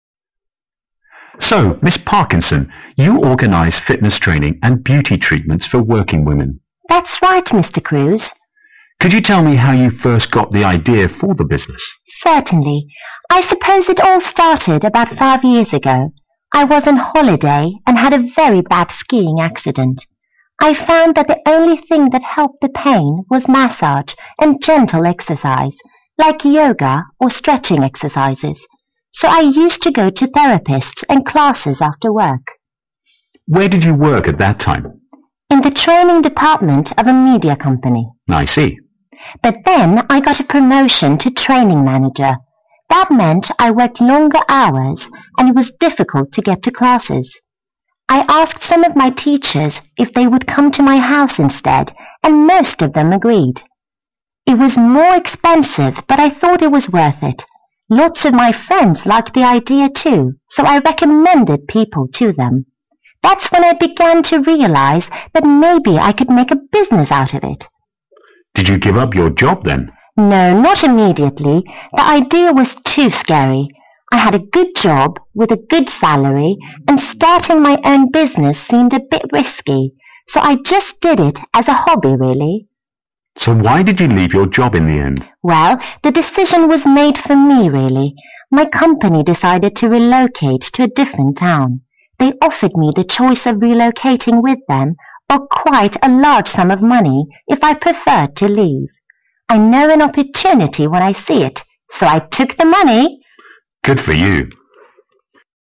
2014年英语专业四级听力真题 对话2